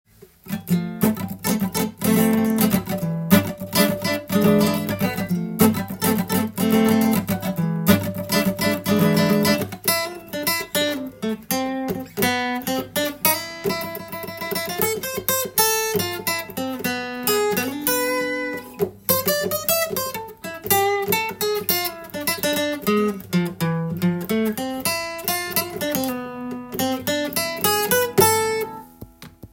カンタンスパニッシュコード
F/E7
全ての音源で適当に弾いているだけですが
④は、スペインの香りがするメランコリックなコード進行です。